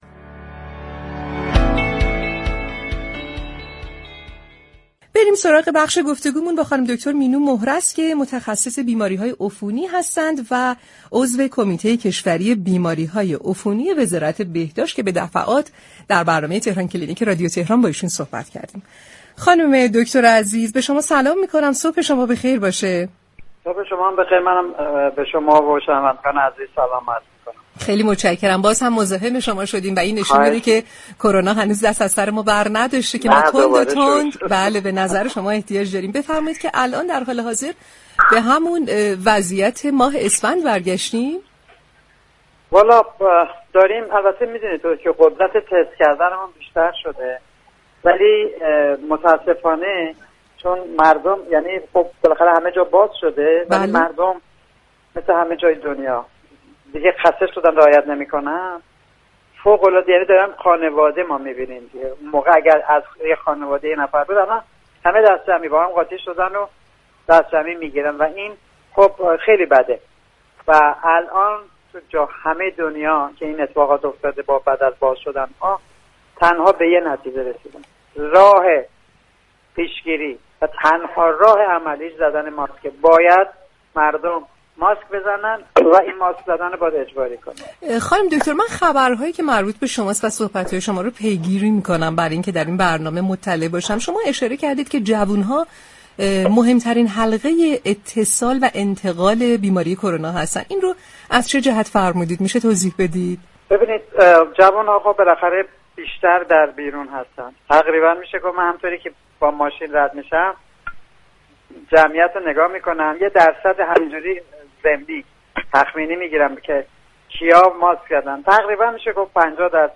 این متخصص بیماری های عفونی با شنیدن واژه "كرونا پلاس" توسط مجری برنامه خندید و گفت: من به مردم پیشنهاد می كنم به مطالب فضای مجازی اطمینان نكنید و خبرها را از رادیو تلویزیون پیگیری كنید.